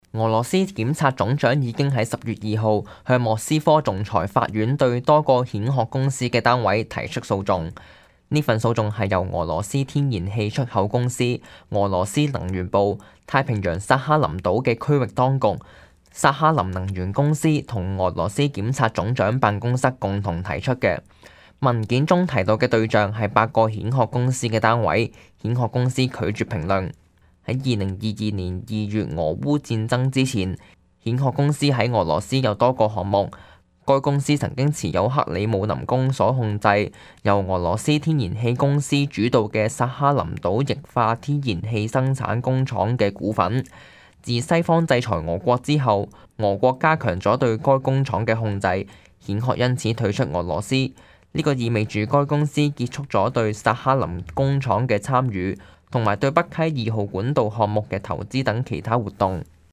news_clip_20814.mp3